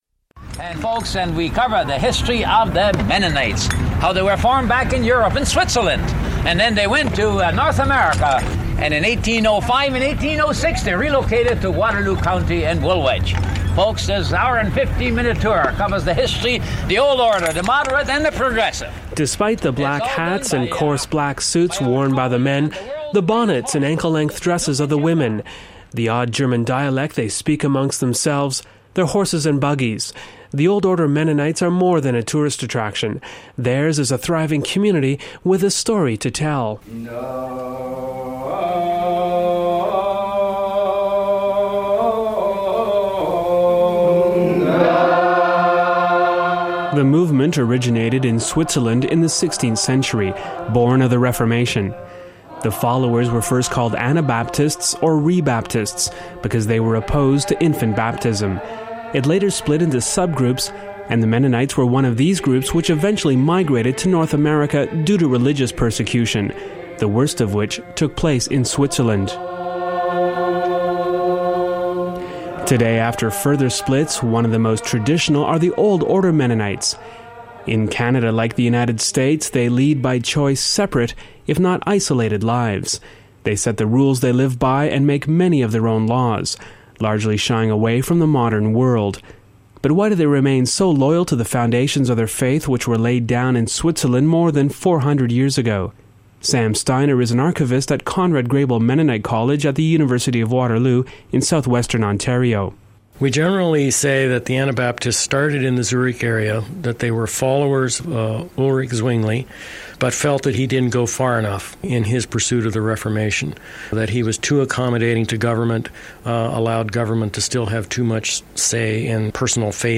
An audio feature from the Swiss Radio International archives